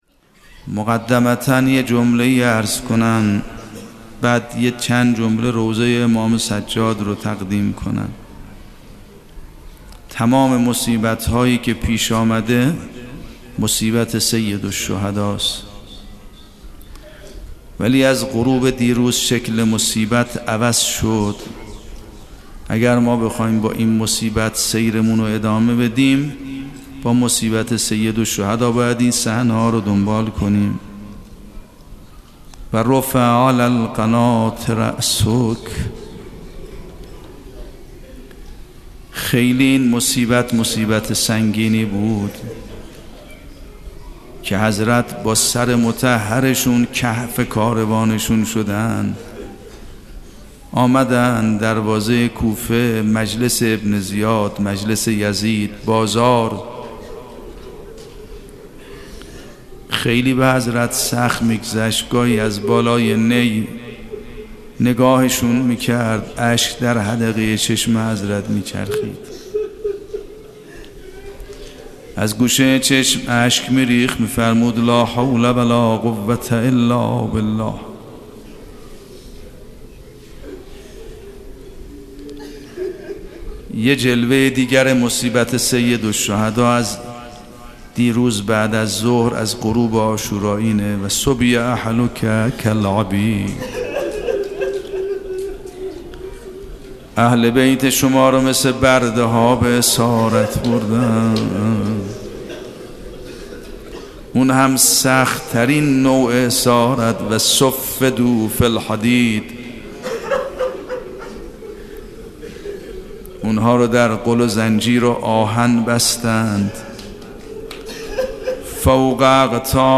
آنچه پیش رو دارید دوازدهمین جلسه سخنرانی آیت الله سید محمد مهدی میرباقری؛ رئیس فرهنگستان علوم اسلامی قم است که در دهه اول محرم الحرام سال ۱۳۹۷در هیأت ثارالله قم (مدرسه فیضیه) برگزار شده است.
roze.mp3